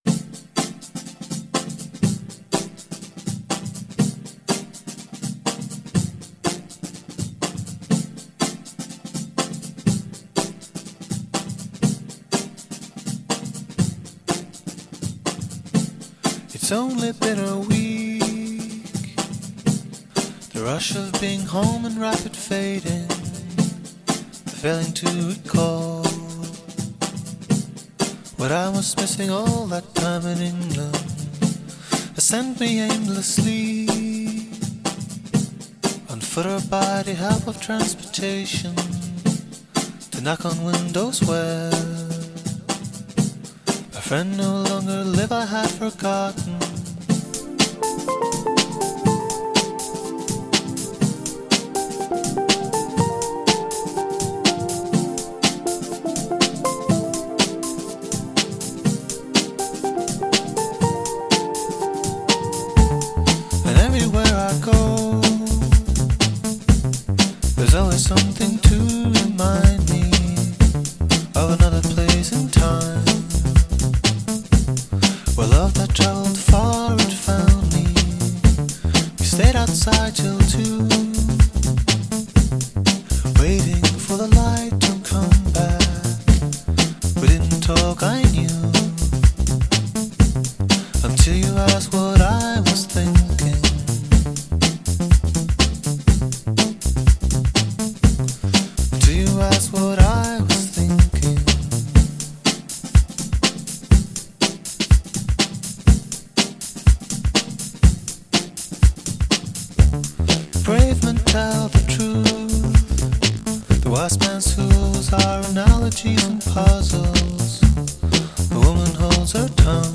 Norwegian duo